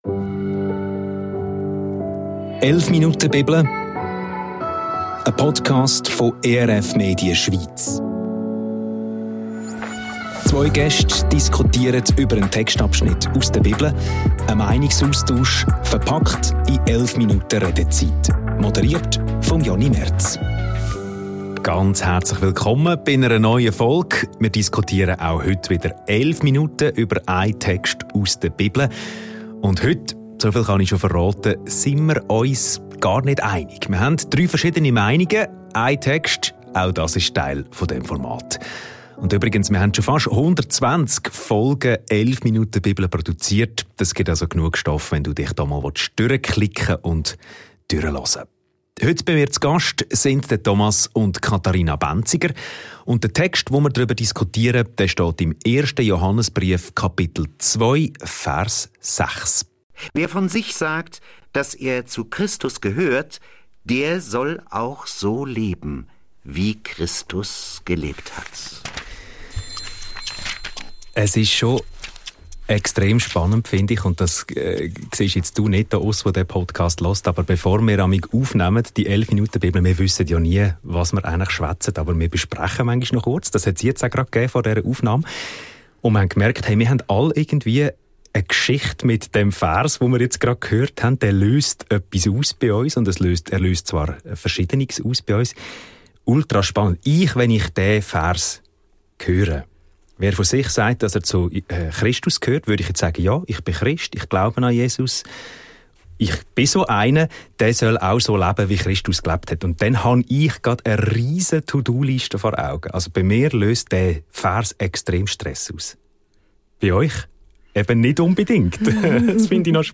Ein Bibeltext, drei Meinungen.